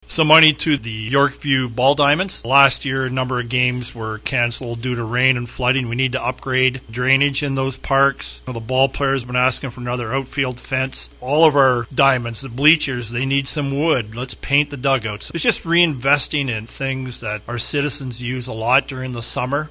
Grantham expanded a bit on the dollar allocation.